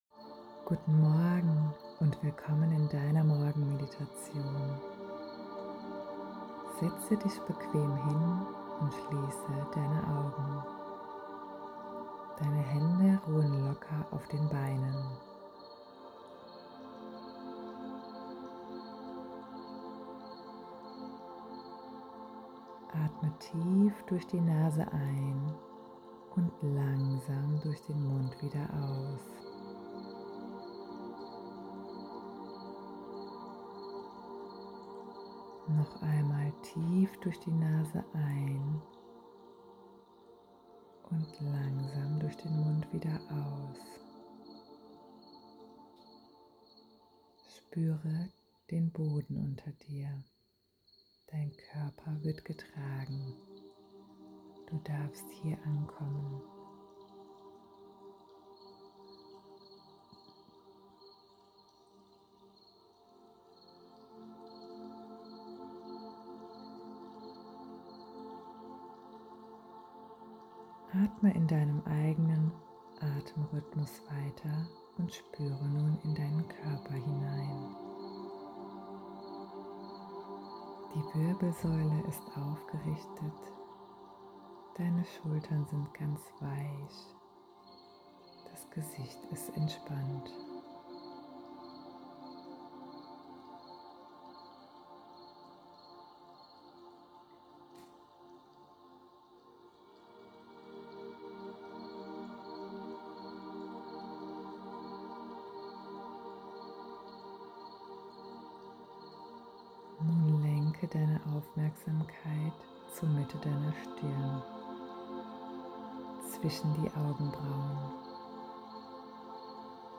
Morgenmedition
Morgenmeditation.mp3